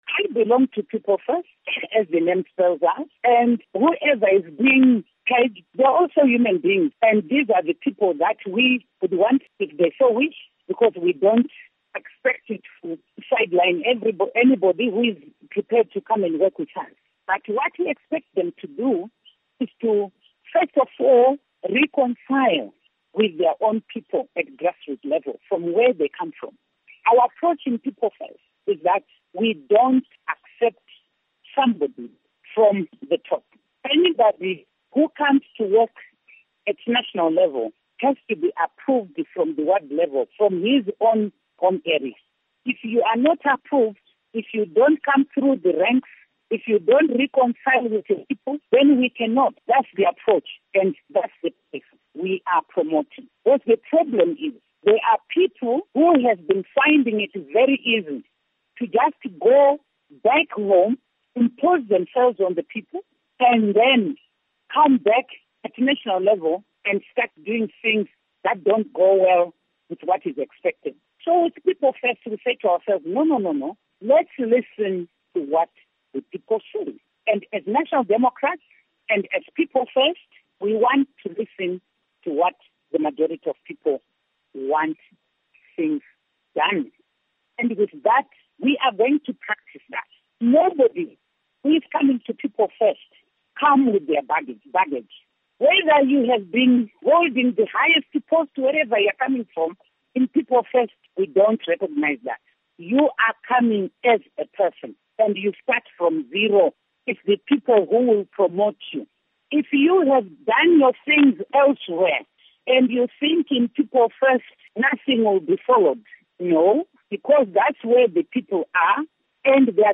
Interview With Joice Mujuru